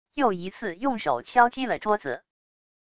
又一次用手敲击了桌子wav下载